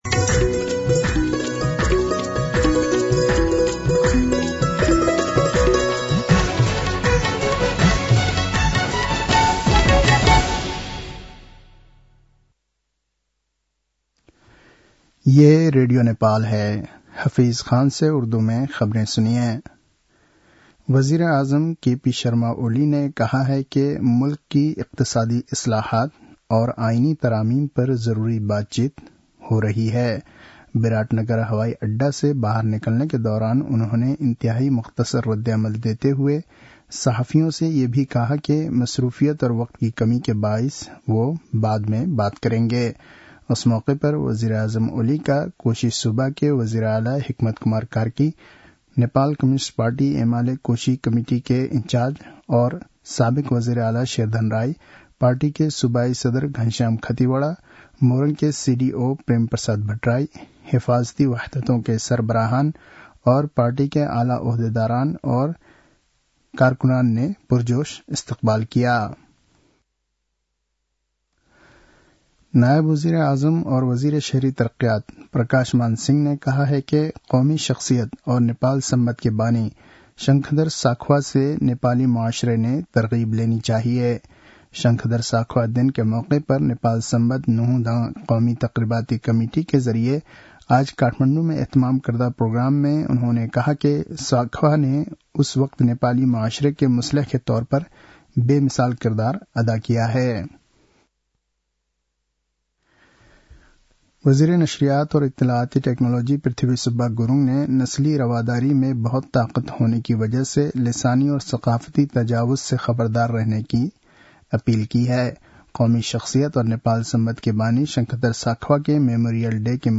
उर्दु भाषामा समाचार : २७ कार्तिक , २०८१